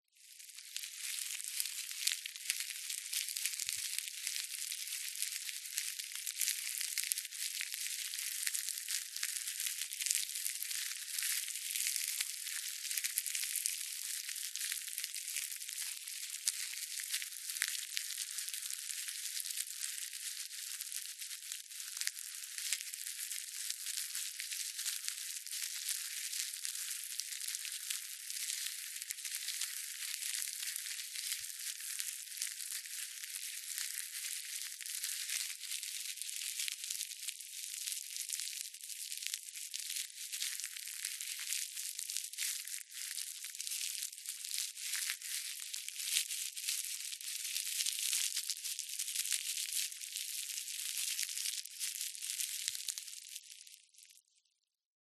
На этой странице собраны разнообразные звуки змей: от устрашающего шипения до угрожающих вибраций хвоста.
Звук сброшенной змеиной кожи